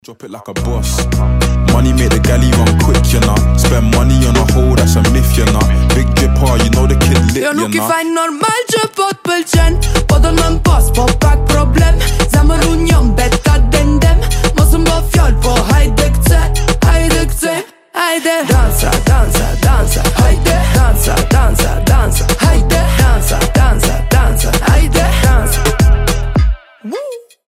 • Качество: 320, Stereo
ритмичные
заводные
дуэт
Moombahton